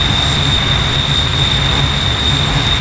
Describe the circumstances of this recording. edited to remove pops/cracks